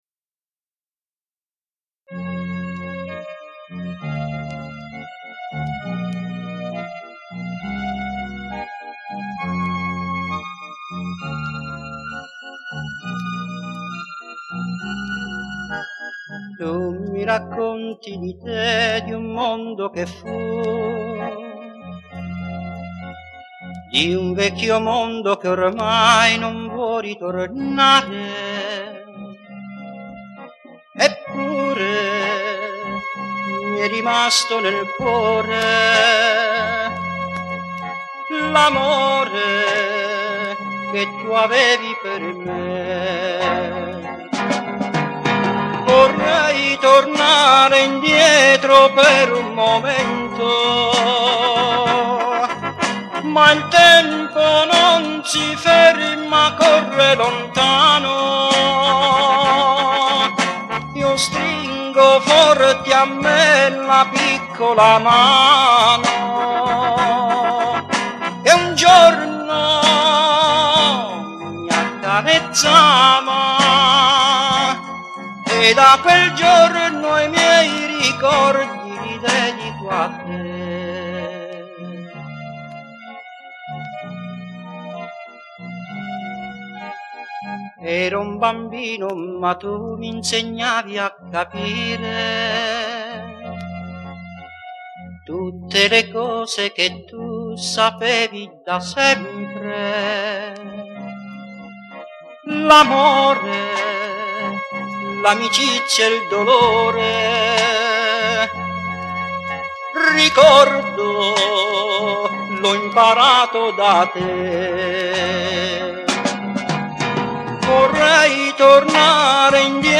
Piano Accordion, Organ, Strings & Electric Piano
Electric & Acoustic Guitars
Electric Bass
Drums
Brass Section
BACKGROUND VOCALS
Recorded at Riversound Recordings, Sydney